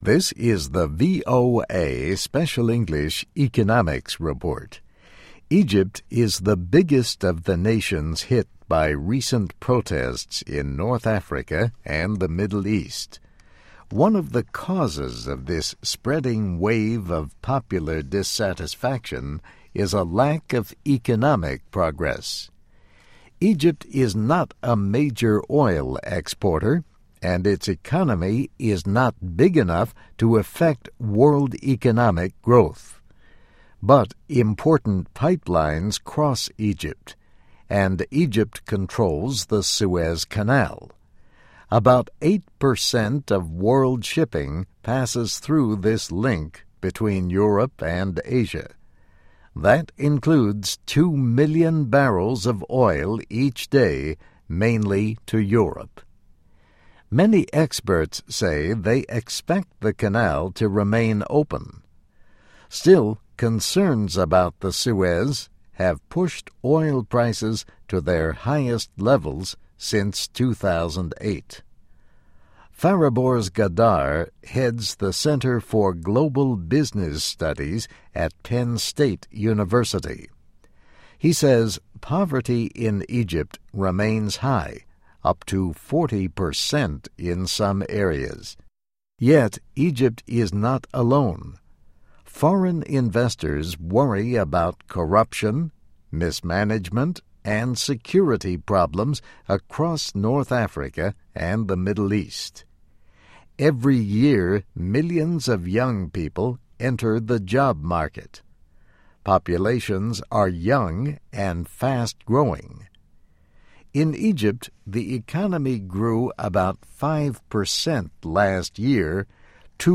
Economics Report